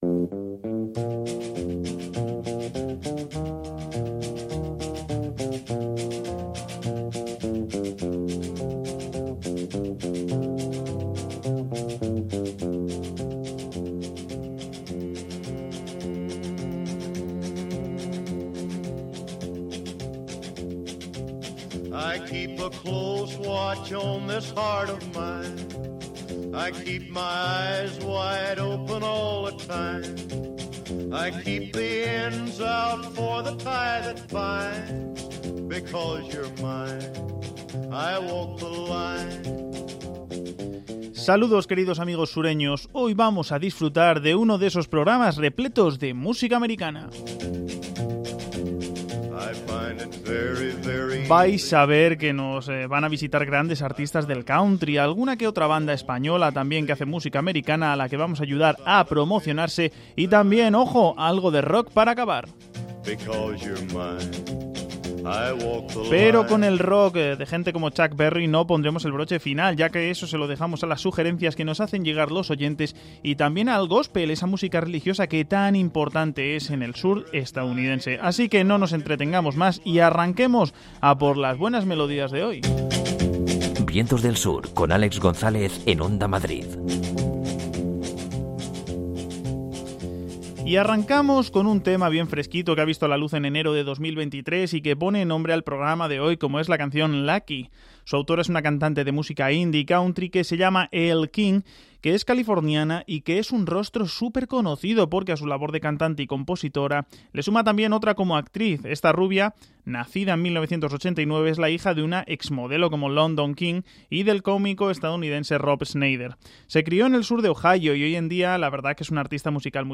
Acaba con el rincón de los oyentes y un tema de góspel.